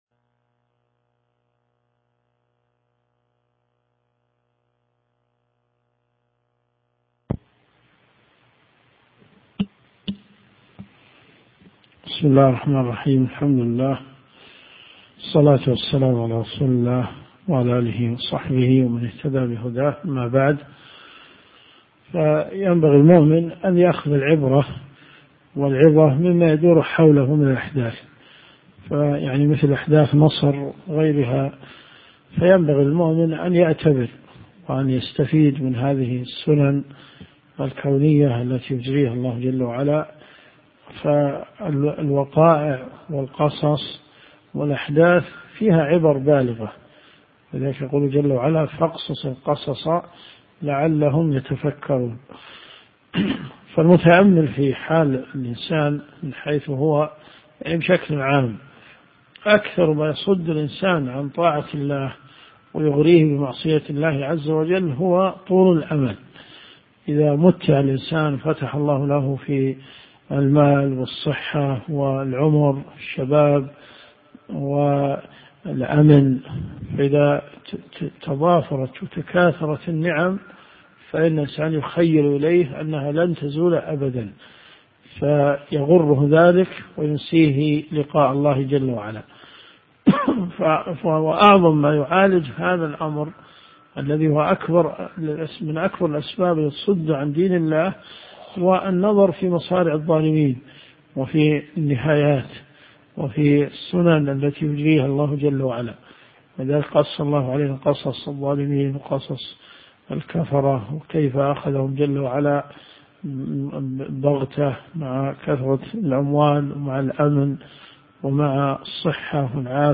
صحيح البخاري . كتاب النكاح - من حديث 5063 -إلى- حديث 5064 - الدرس بدء في الدقيقة 20.15 - الأحـد .